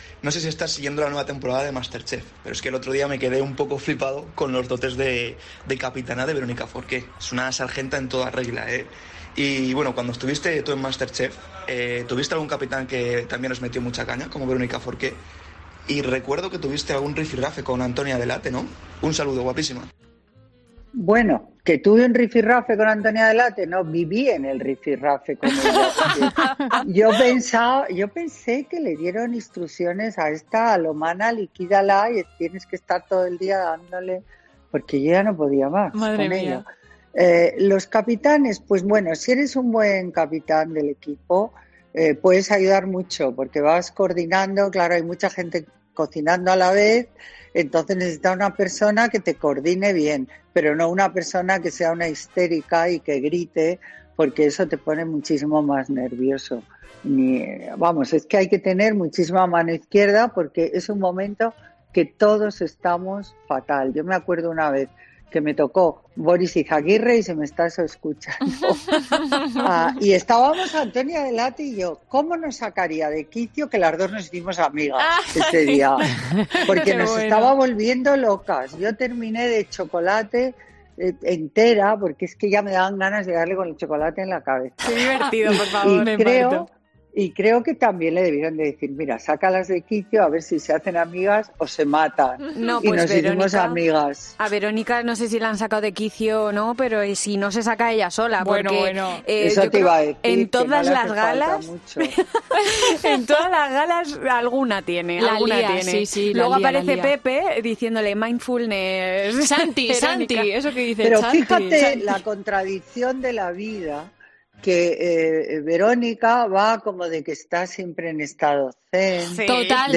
Todo ello este sábado durante el consultorio de COPE en el que da respuesta a las dudas de los oyentes y, en este caso, querían saber su opinión sobre el papel que está cumpliendo Verónica Forqué en la presente edición. “Fíjate la contradicción de la vida, que Verónica va siempre de que está en estado zen”, comentaba la colaboradora, antes de que le interrumpiese la presentadora, Cristina López Schlichting.